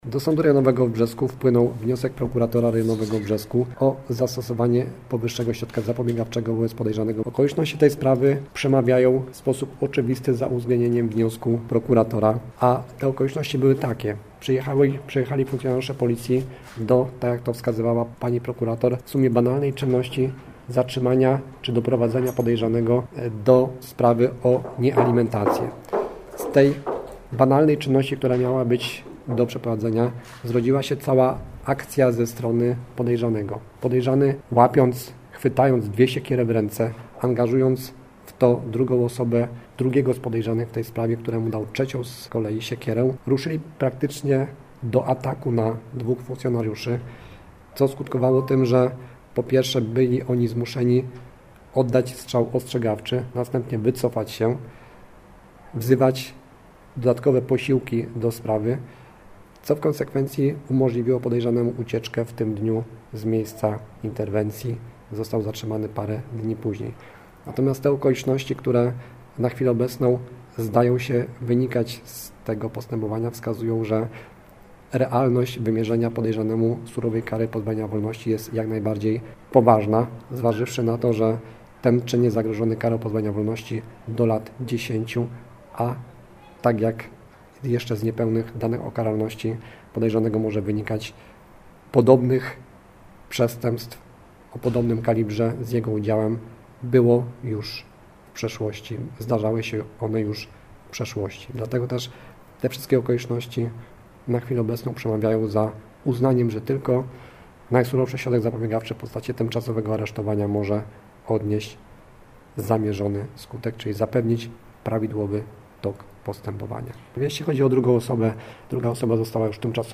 Mężczyznom grozi do 10 lat pozbawienia wolności. Uzasadnienie wyroku. Jaromierz Sobusiak, sędzia Sądu Rejonowego w Brzesku.